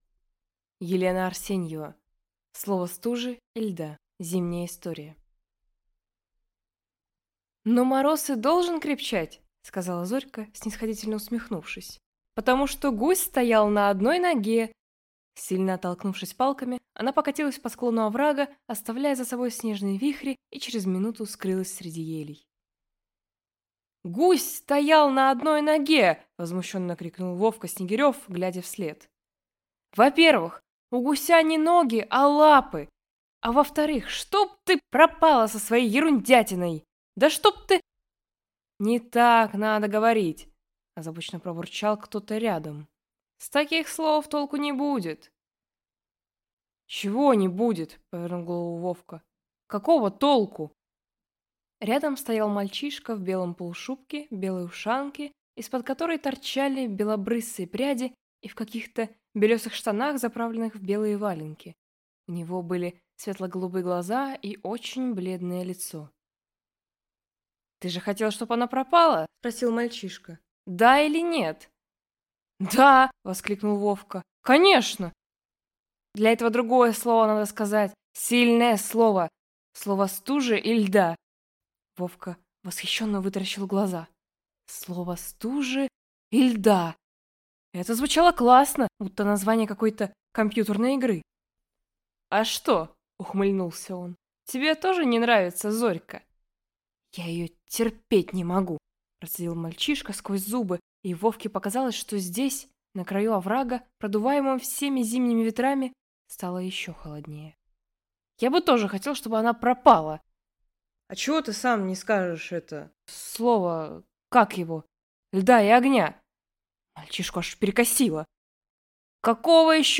Аудиокнига Слово стужи и льда | Библиотека аудиокниг